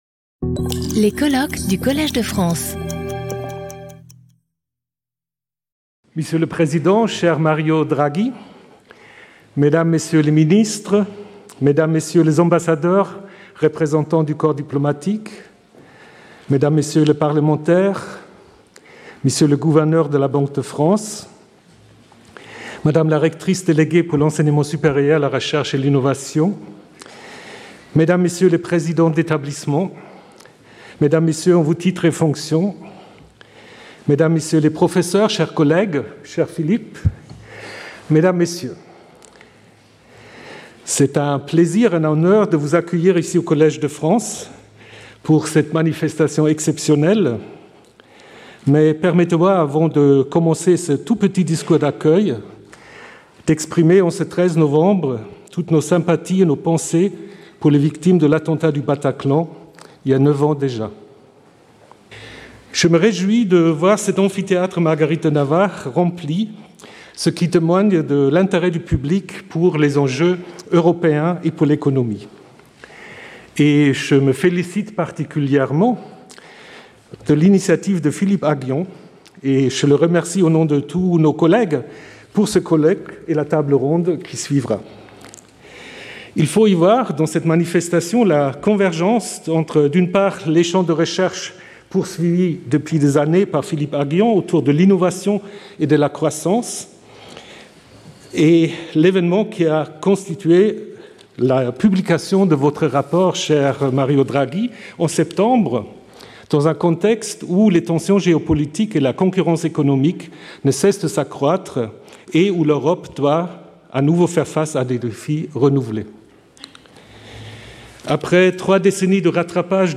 Présentation par Philippe Aghion (Collège de France, INSEAD, London School of Economics)
Colloque